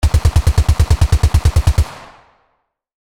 Hundreds of professionally recorded War Sound Effects to download instantly, from Pistols, Missiles and Bombs, Guns, Machine guns and Sniper Rifles!
AK-47-assault-rifle-distant-fire.mp3